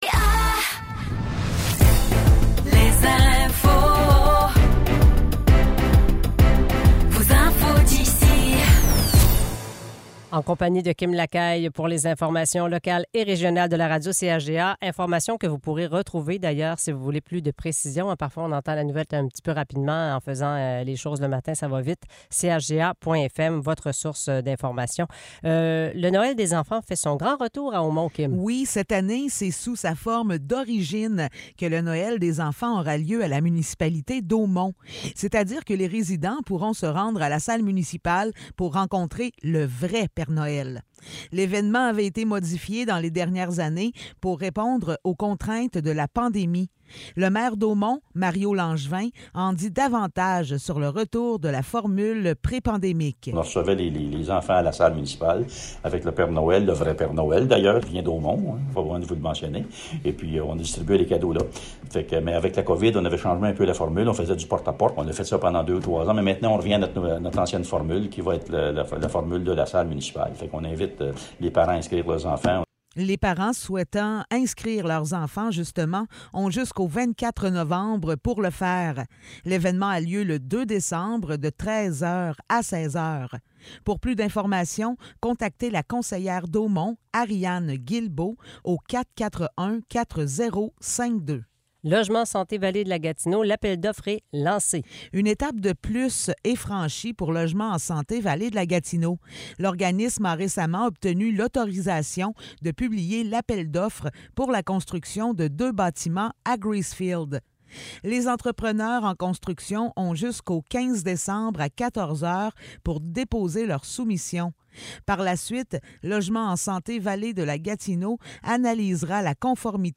Nouvelles locales - 10 novembre 2023 - 7 h